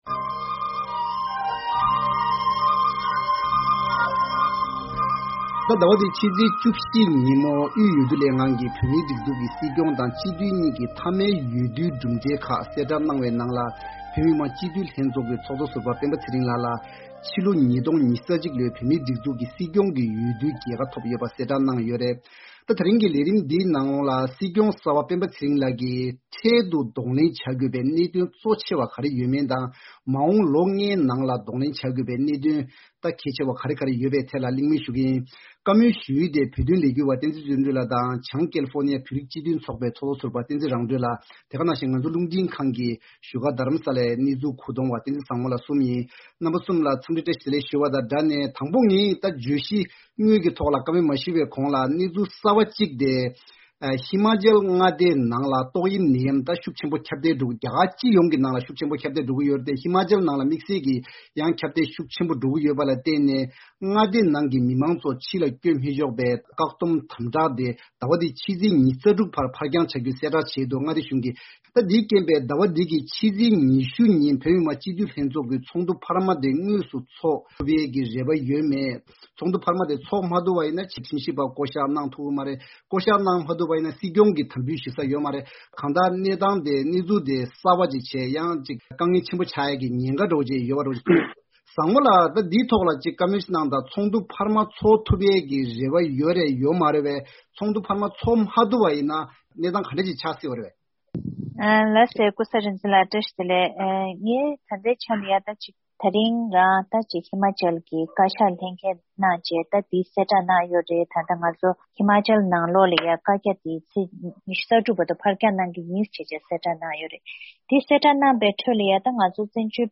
༄༅། གཟའ་འཁོར་འདིའི་བགྲོ་གླེང་བདུན་ཅོག་ལས་རིམ་ནང་བོད་མིའི་སྒྲིག་འཛུགས་ཀྱི་སྲིད་སྐྱོང་ལ་འདེབས་ཐོན་བྱུང་བ་སྤེན་པ་ཚེ་རིང་ལགས་སུ་མ་འོངས་པའི་ལོ་ལྔའི་རིང་གོ་སྐབས་དང་གདོང་ལེན་བྱ་དགོས་པའི་གནད་དོན་གང་ཡོད་པ་བཅས་ཀྱི་སྐོར་ལ་གླེང་མོལ་ཞུ་ཡི་རེད།